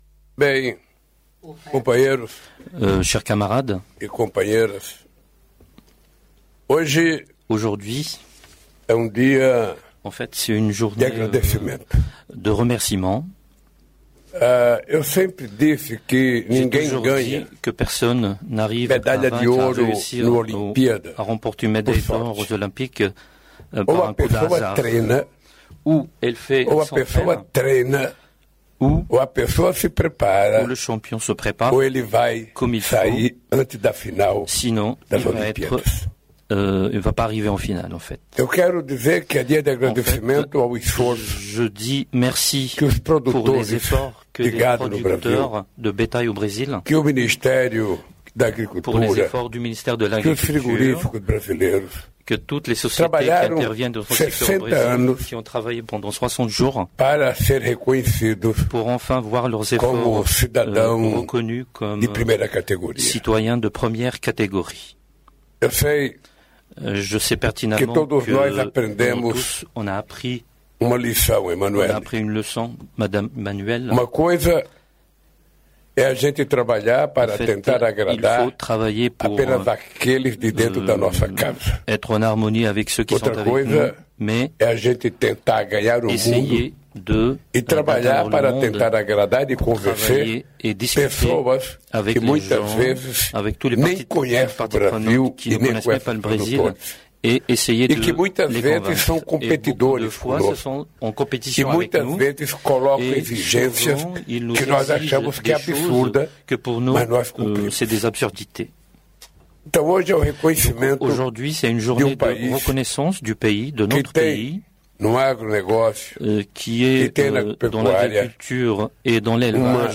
Presidente destacou a importância da educação como ferramenta de transformação social e combate à desigualdade, nesta sexta-feira (6), em Paris. No discurso, ressaltou a miscigenação como uma das maiores riquezas do Brasil. Lula falou ainda sobre os programas sociais que permitiram a entrada dos mais pobres na universidade.